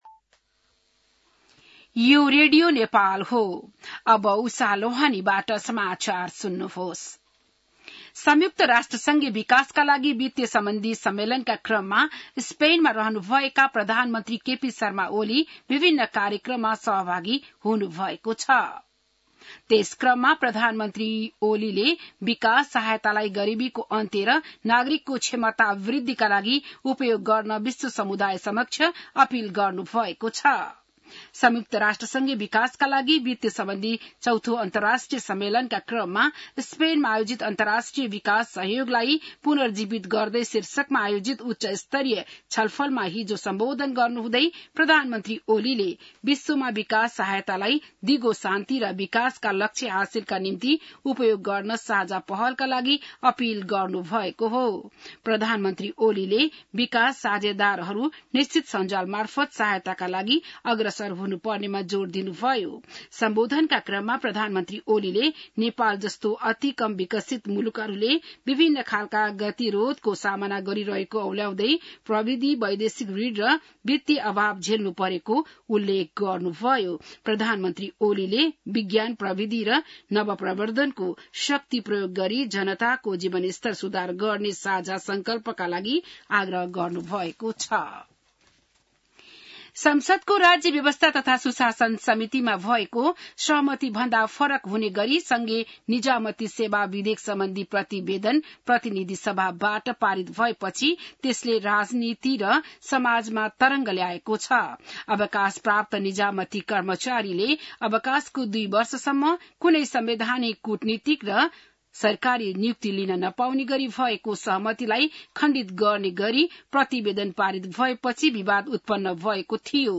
बिहान १० बजेको नेपाली समाचार : १८ असार , २०८२